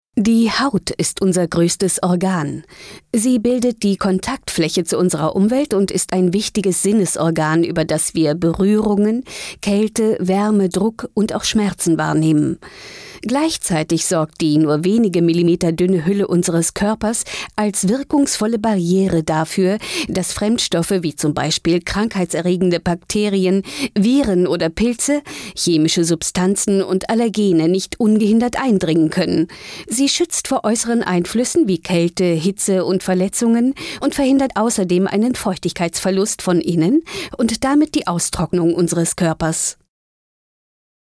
Dein Browser unterstüzt kein HTML5 Sachtext Drama